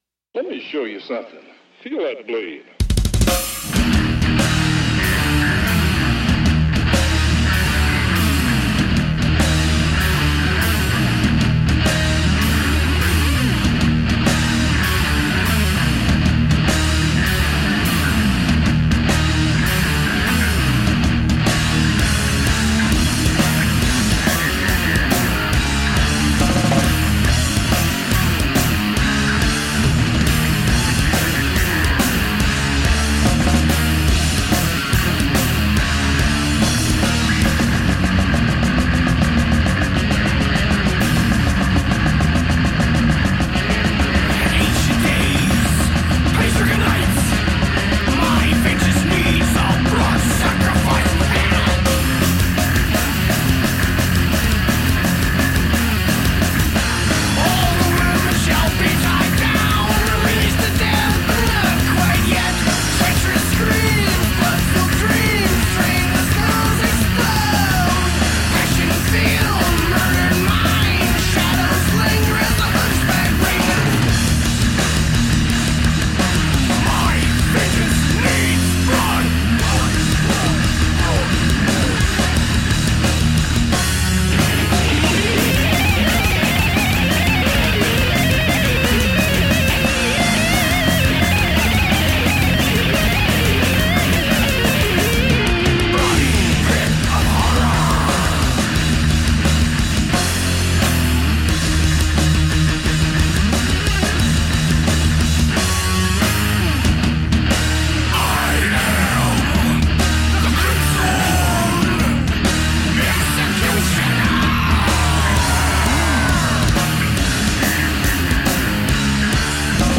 Hard, wild, thrashing, punk-edged heavy metal.
Tagged as: Hard Rock, Metal, Intense Metal